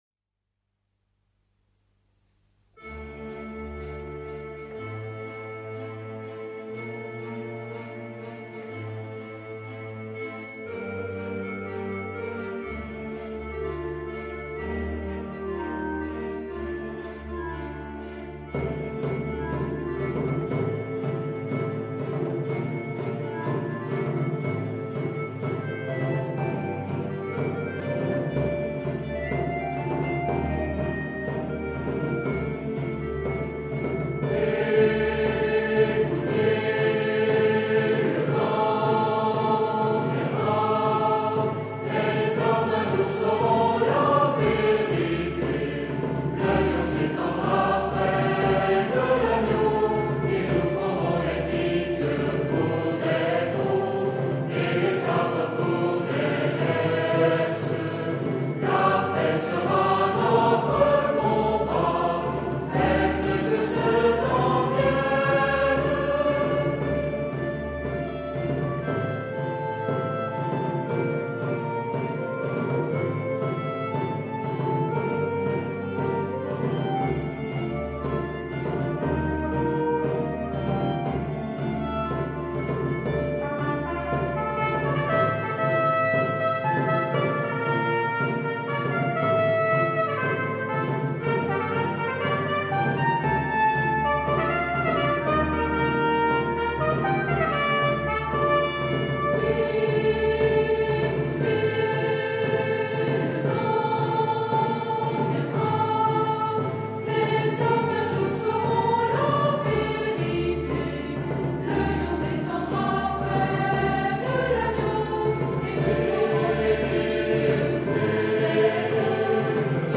Articles similaires Catégories Altos , Basses , Grand Chœur , Répétitions , Sopranos , Ténors